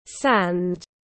Cát tiếng anh gọi là sand, phiên âm tiếng anh đọc là /sænd/.